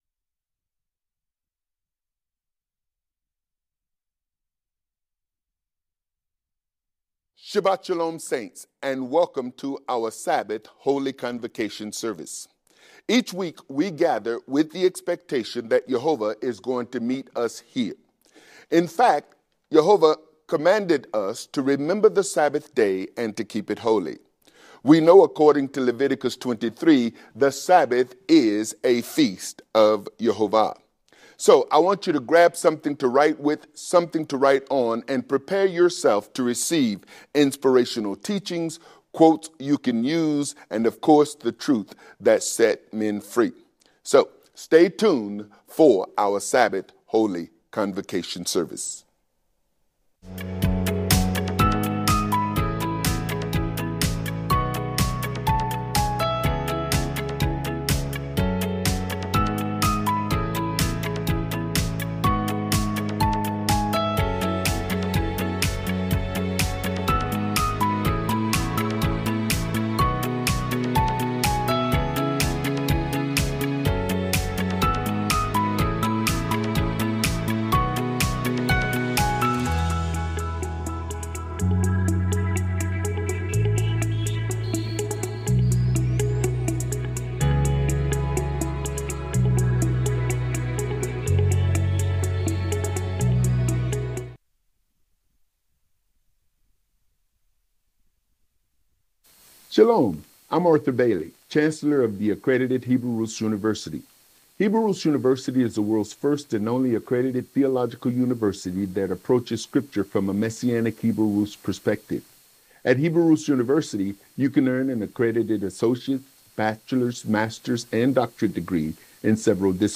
Sabbath-Service_-Becoming-Like-Messiah.mp3